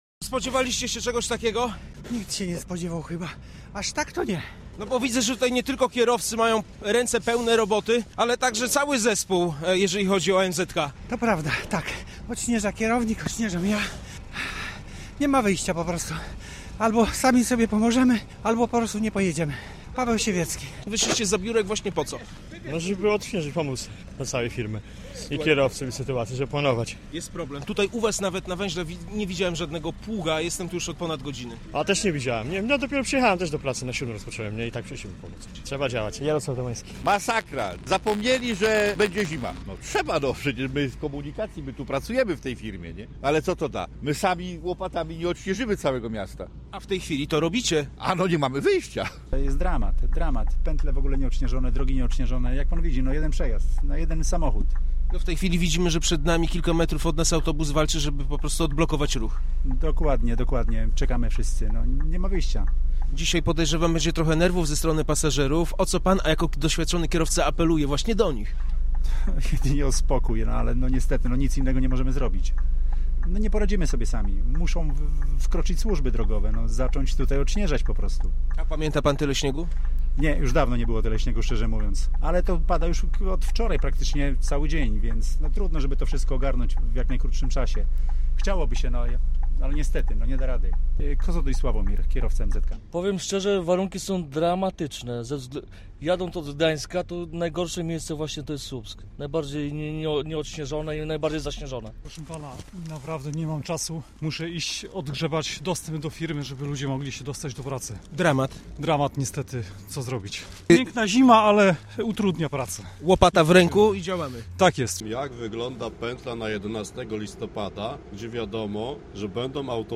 Posłuchaj relacji naszego reportera: